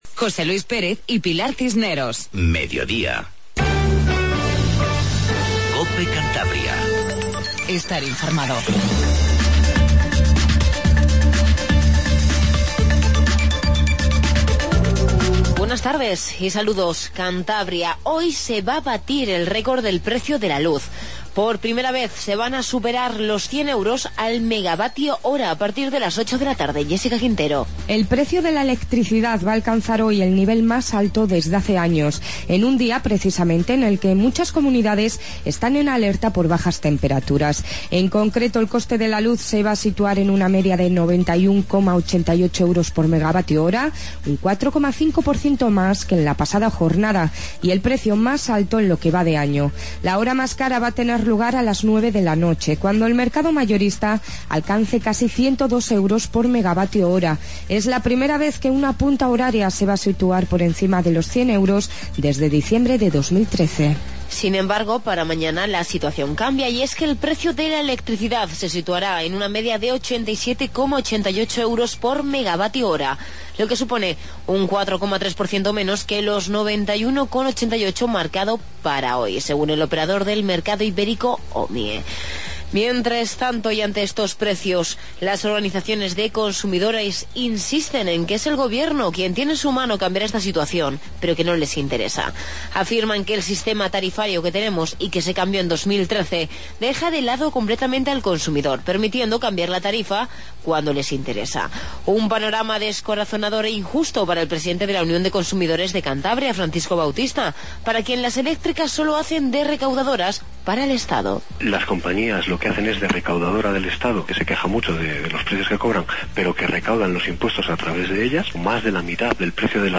INFORMATIVO REGIONAL 14:50